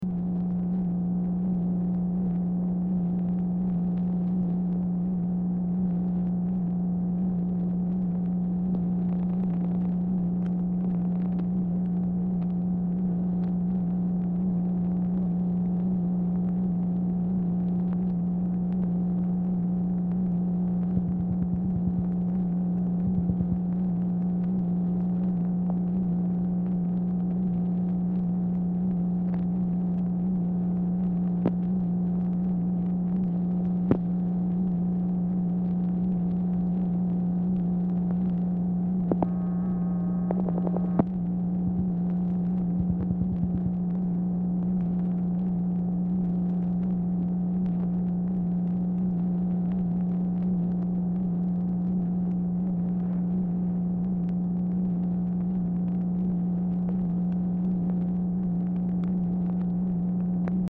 Telephone conversation # 9443, sound recording, MACHINE NOISE, 1/6/1966, time unknown | Discover LBJ
Telephone conversation
Format Dictation belt
Location Of Speaker 1 Mansion, White House, Washington, DC